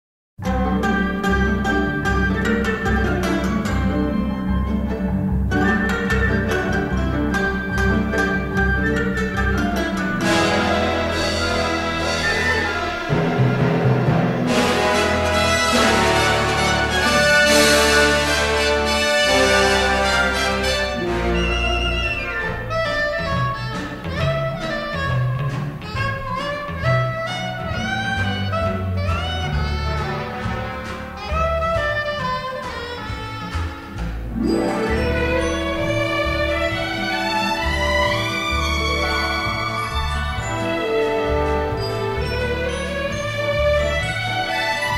released in stereo in 1959